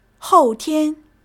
hou4 tian1.mp3